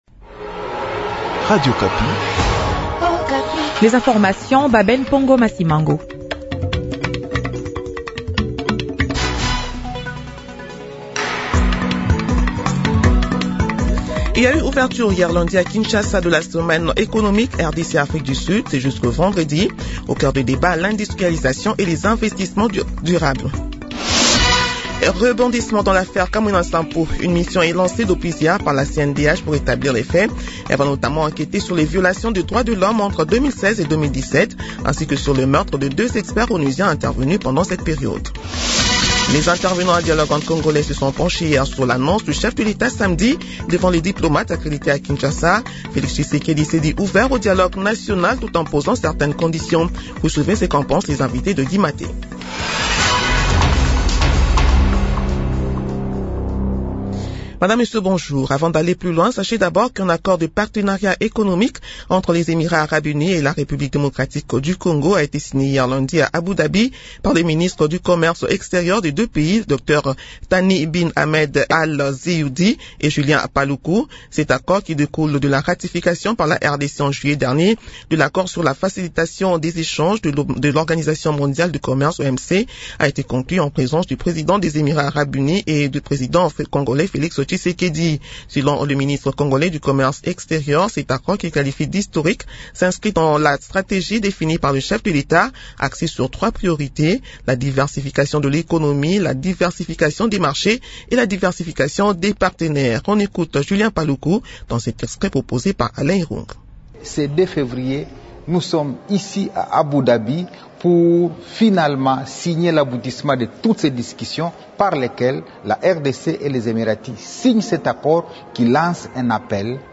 Journal de 8 heures du mardi 03 février 2026